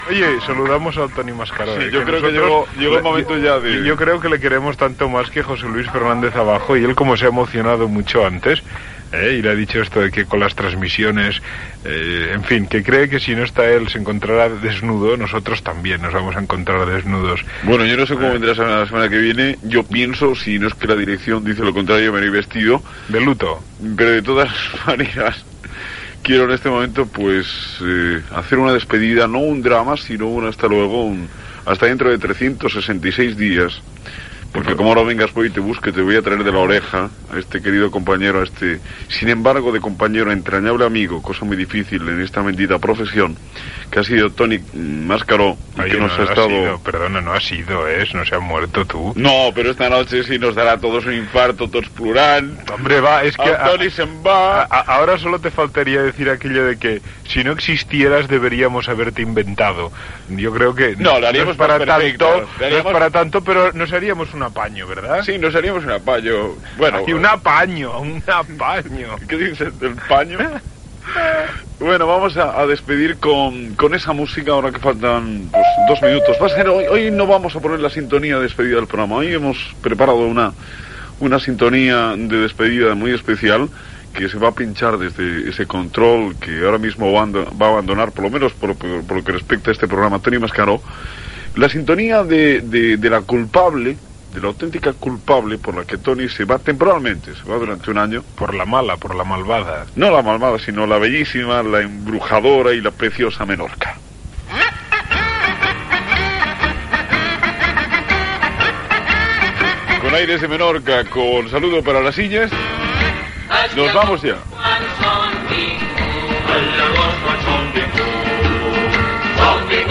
Entreteniment
Magazín dels diumenges a la tarda i el capvespre.